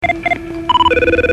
CTU-Telefon Klingelton als WAV (229 kb)